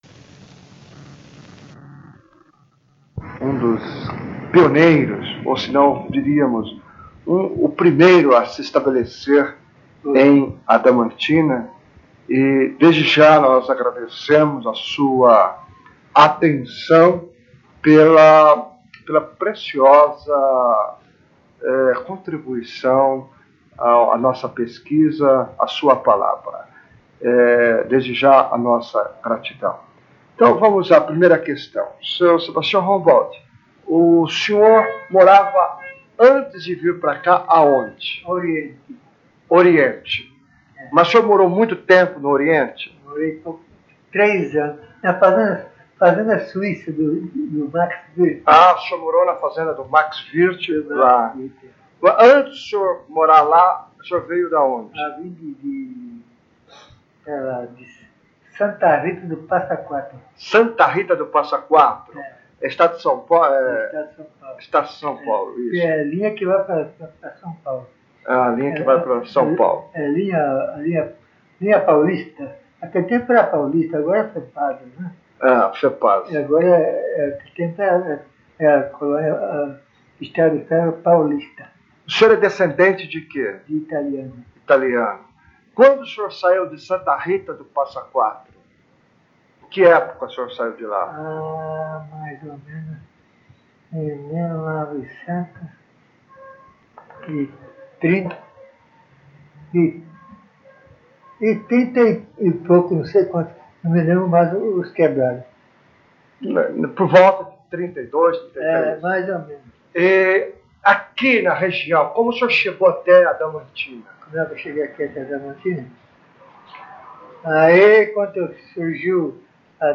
*Recomendado ouvir utilizando fones de ouvido.